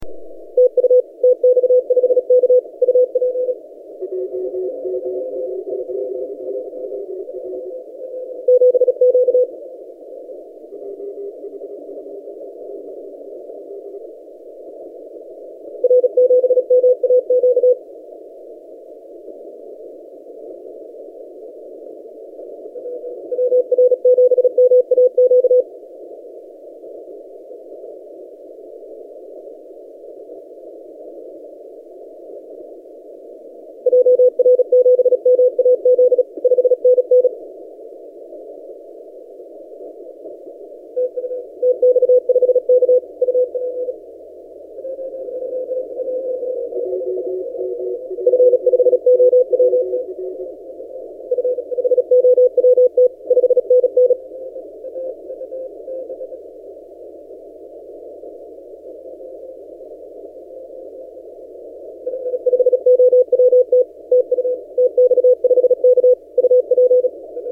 TX5K 30 CW